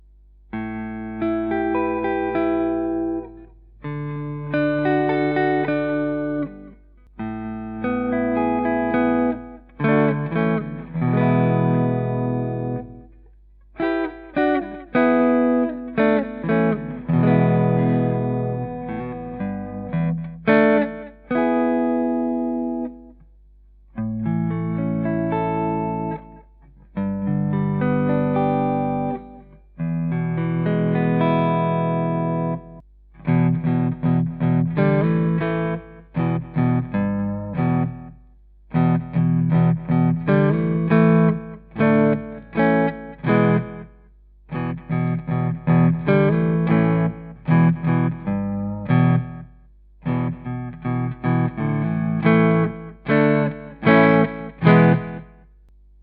Archangel alnico 3 Soapbar P90, smooth clear tone
A traditional vintage winding together with alnico 3 magnets provides a softer attack, low string pull and great sustain combined with a balance between clarity and warmth that avoids both harshness and excess mids.